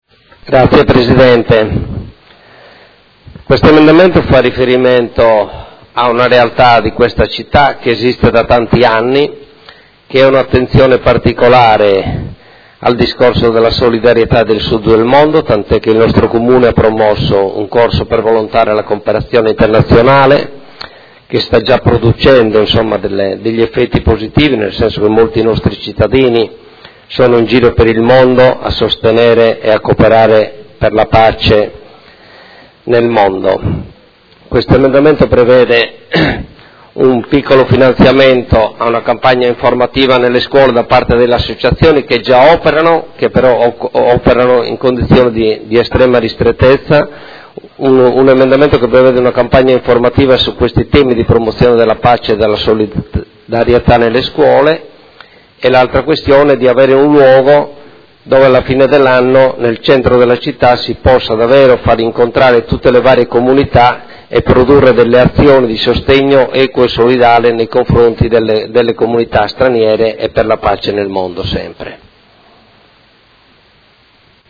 Seduta del 25 febbraio. Approvazione Bilancio: presentazione emendamento Prot. 21613